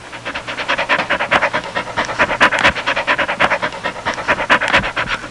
Panting Dog Sound Effect
Download a high-quality panting dog sound effect.
panting-dog.mp3